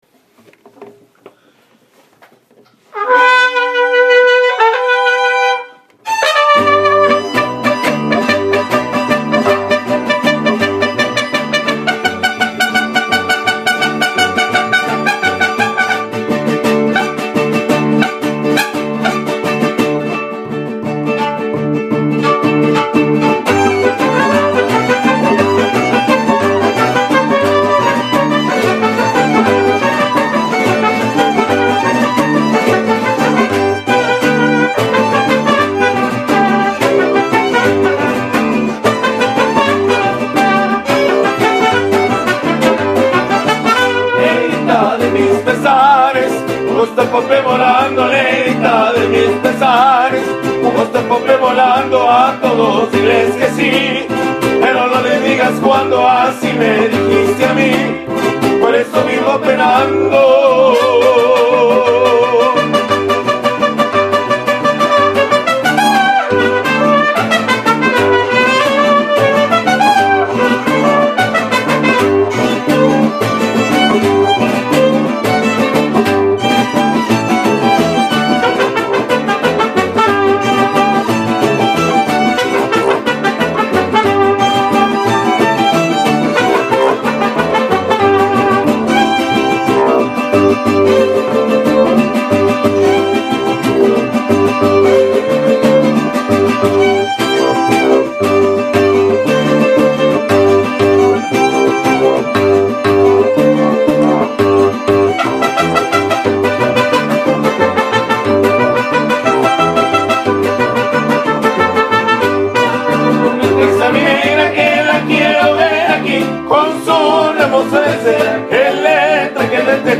This group is comprised of 6 members.